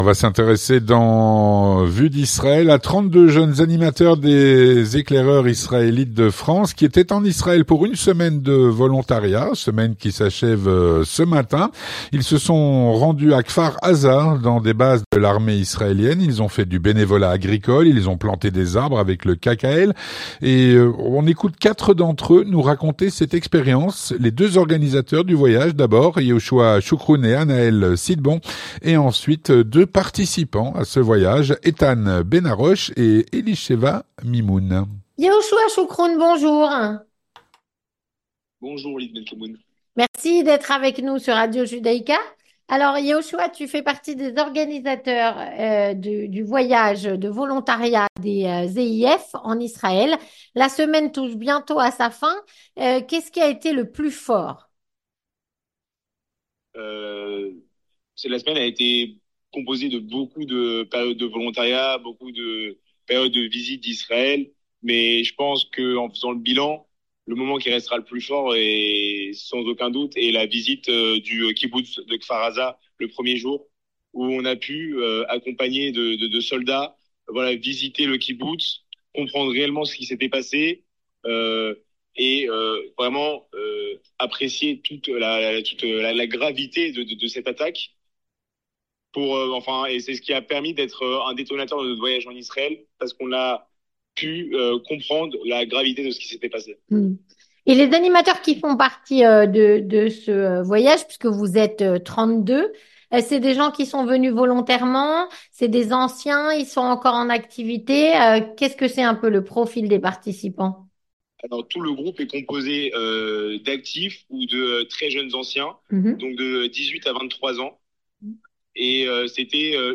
32 jeunes animateurs des EEIF étaient en Israël pour une semaine de volontariat jusqu’à ce matin. Ils se sont rendus à Kfar Aza, dans des bases de l’armée, ont fait du bénévolat agricole, planté des arbres avec le KKL. On écoute 4 d’entre eux nous raconter cette expérience.